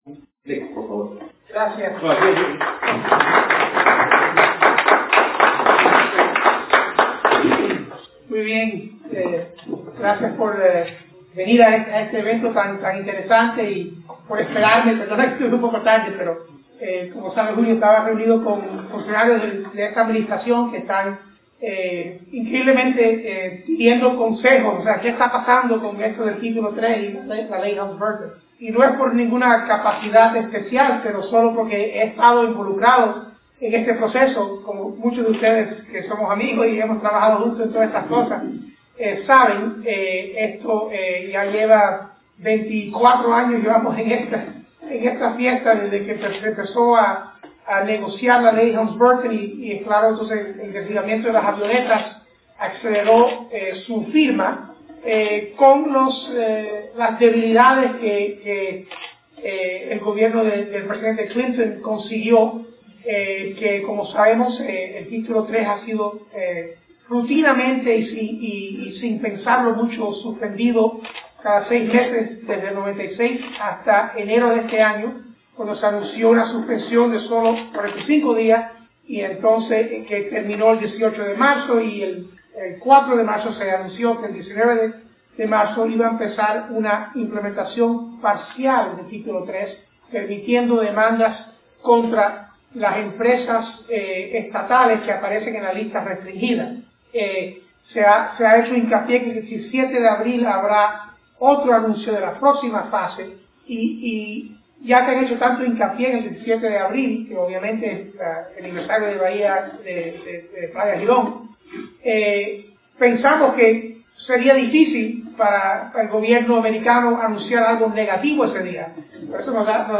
West Dade Regional Library, 21 marzo 2019
Audios de programas televisivos, radiales y conferencias